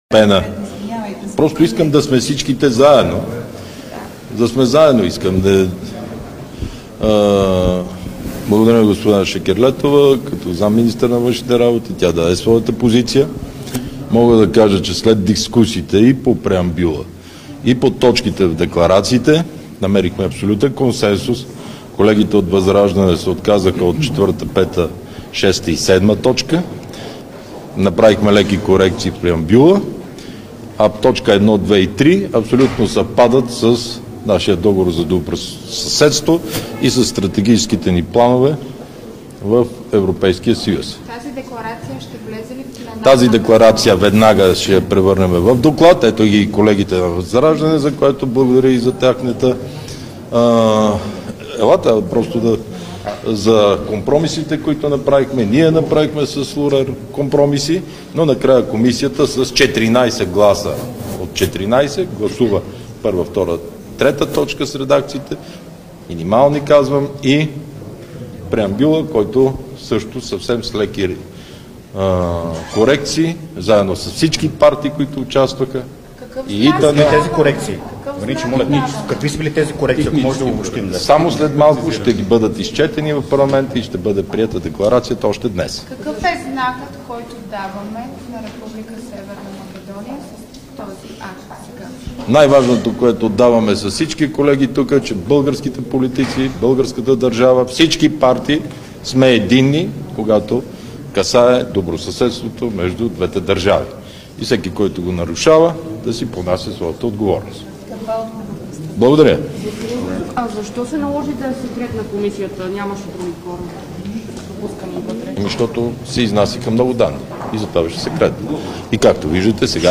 Основни участници в събитието ще бъдат младите и нови кандидат-депутати от БСП-Обединена левица, които този път имат водещи места в листите.
Директно от мястото на събитието